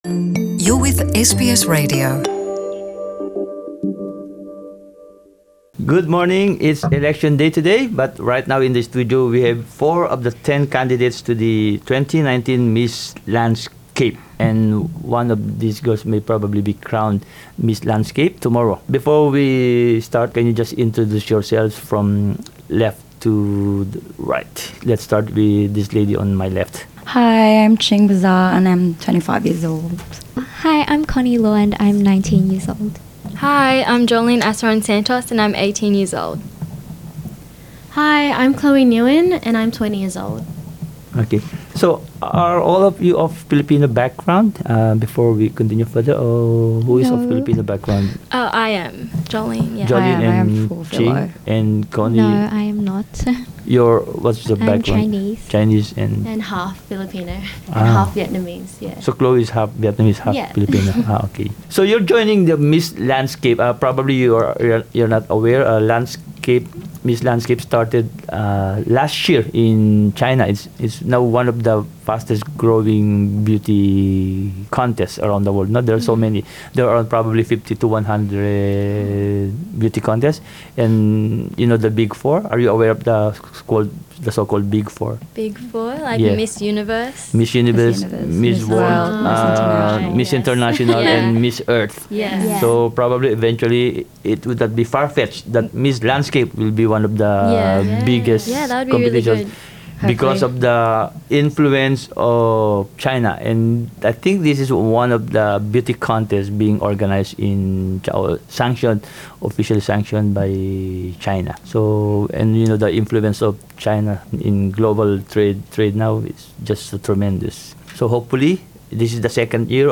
We ask four of the 10 candidates of Miss Landscapes International Australia - who are all millennials, about what they think should be done.